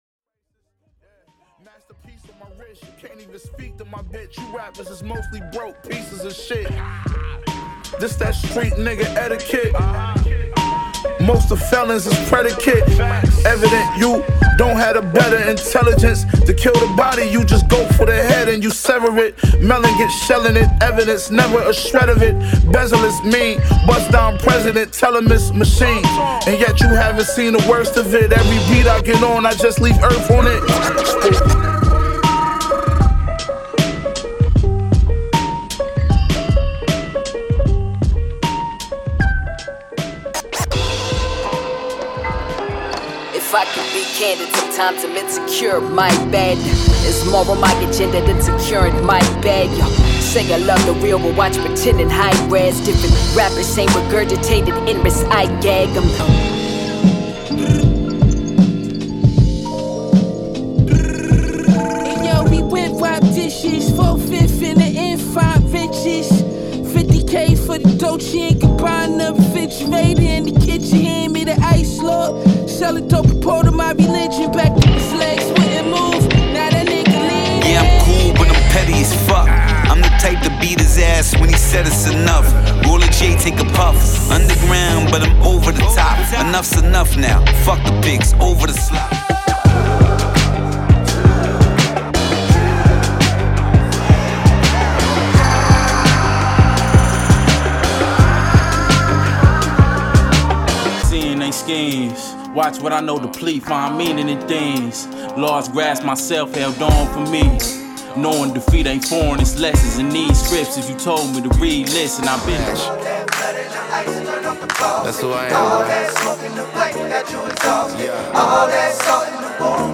過去に敬意を払いつつハードで現代的、シーンを席巻し続けるブーンバップ楽曲中心の序盤。